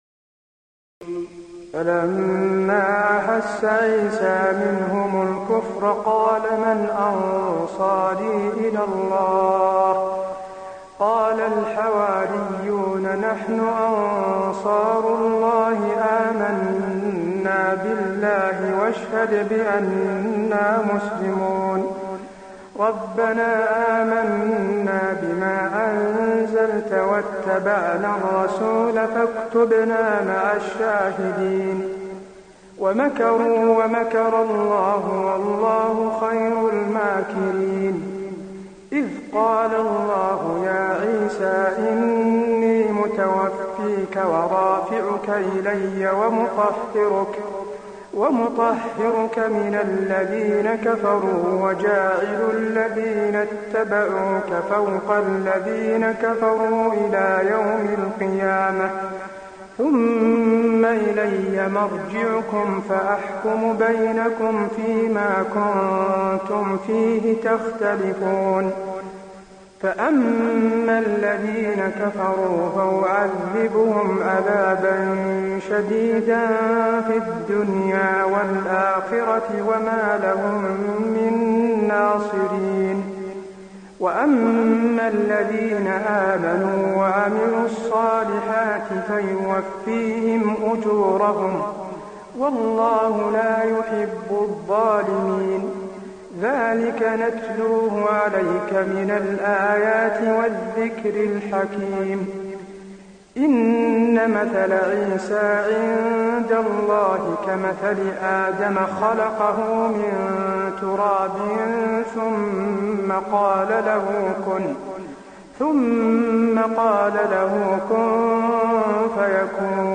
تراويح الليلة الثالثة رمضان 1423هـ من سورة آل عمران (52-92) Taraweeh 3st night Ramadan 1423H from Surah Aal-i-Imraan > تراويح الحرم النبوي عام 1423 🕌 > التراويح - تلاوات الحرمين